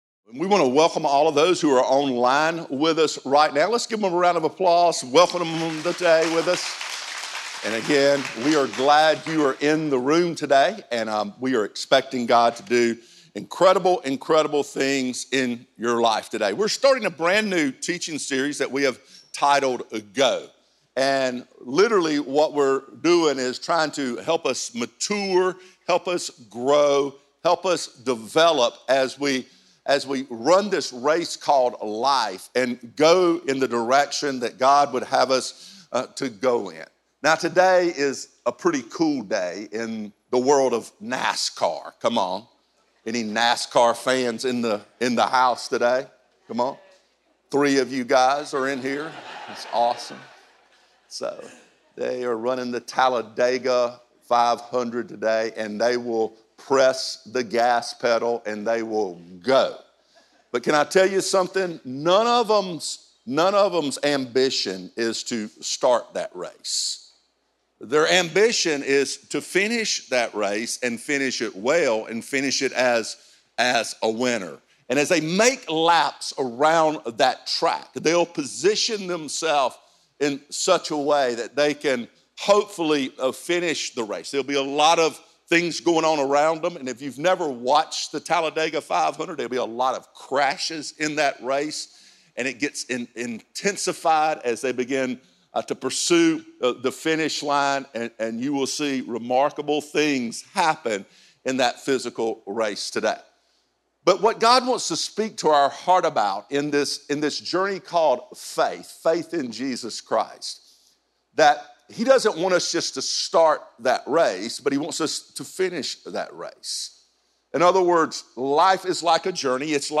a dynamic, high-energy speaker with a heart and vision to reach the world